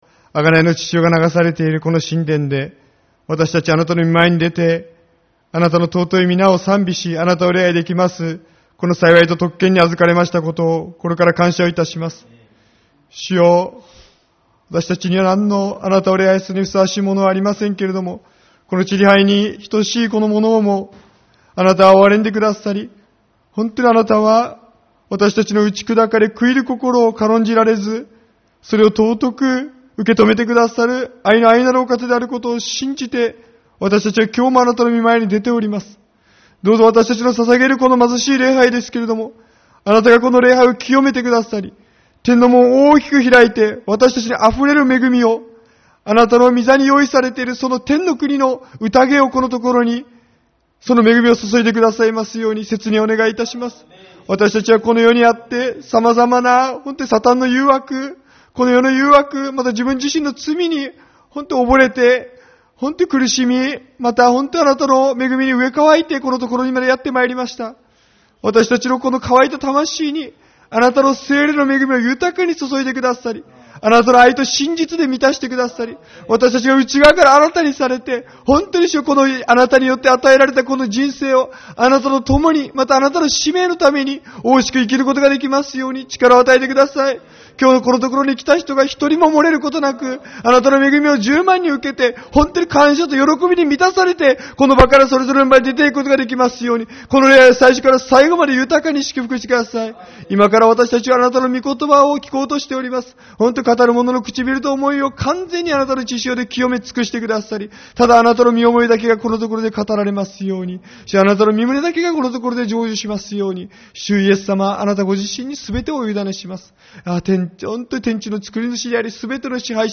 9月1日主日礼拝 「打ち砕かれ悔いる心」